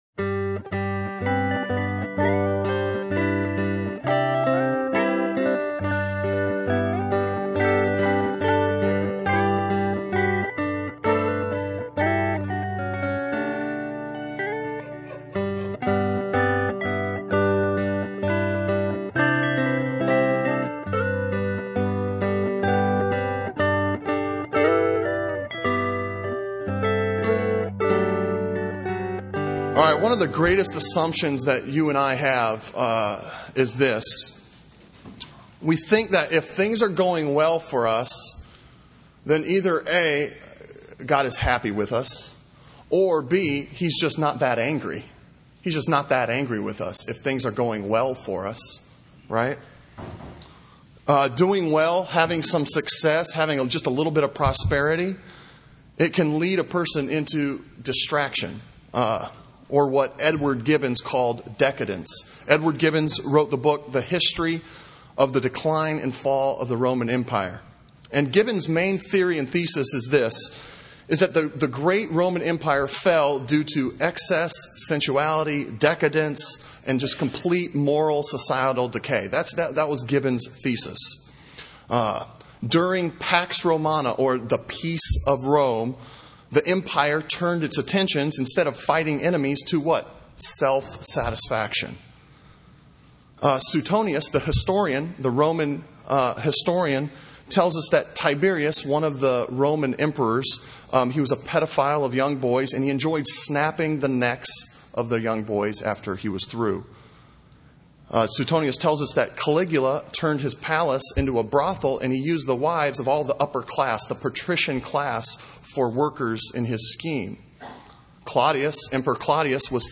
Sermon Audio from Sunday
Sermon on Hosea 1:1 from May 4